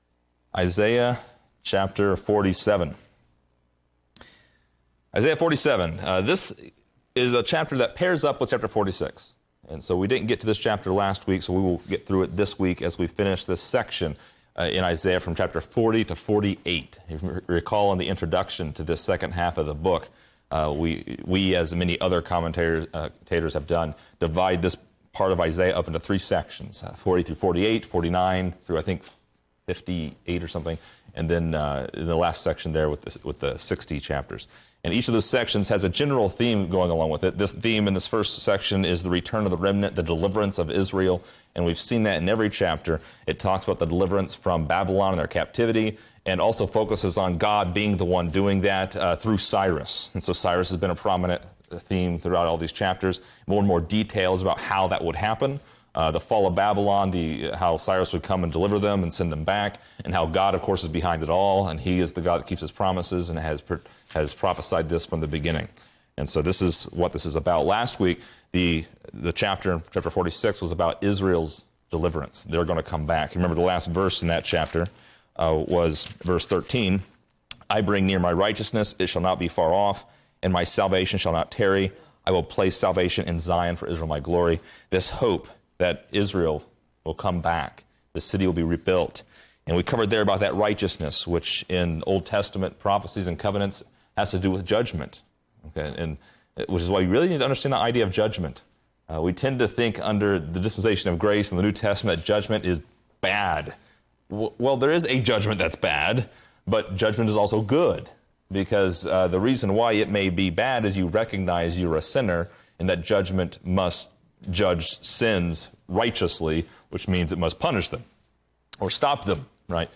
Description: This lesson is part 56 in a verse by verse study through Isaiah titled: Babylon Judged.